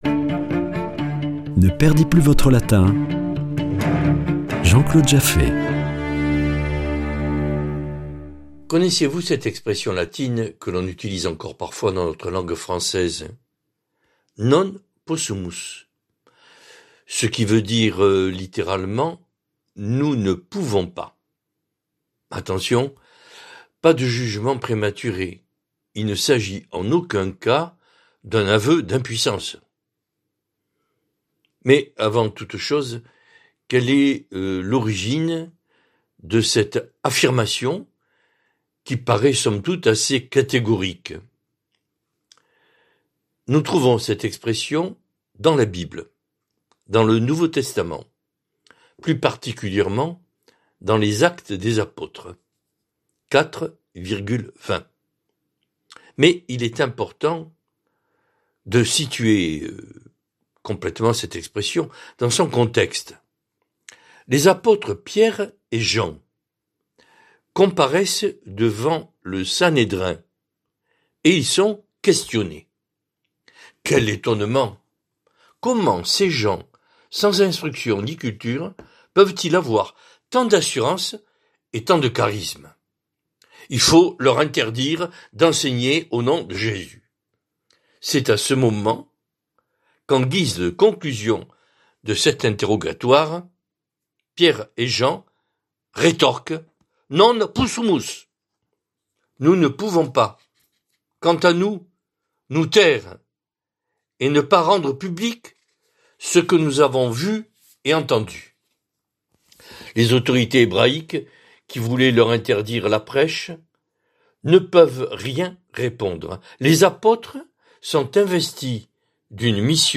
Chronique Latin
Une émission présentée par